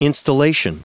Prononciation du mot installation en anglais (fichier audio)
Prononciation du mot : installation